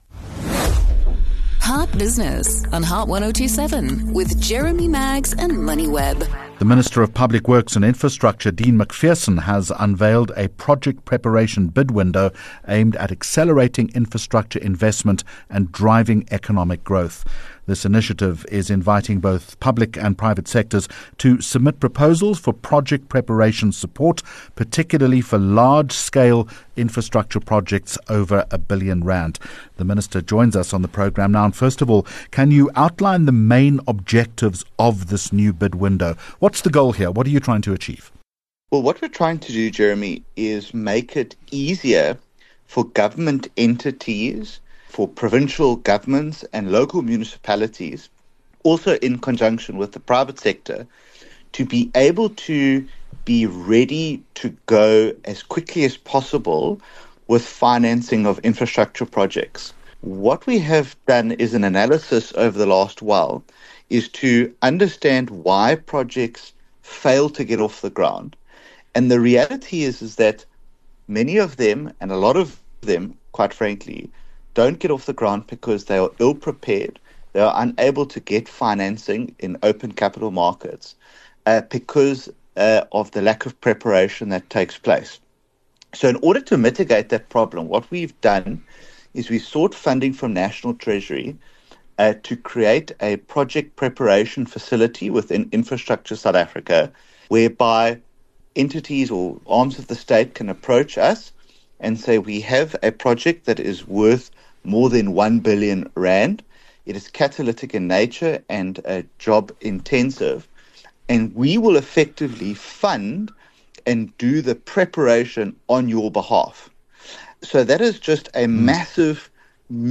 HOT TOPIC Topic: Understanding government’s infrastructure plans Guest: Dean Macpherson -Minister of Public Works & Infrastructure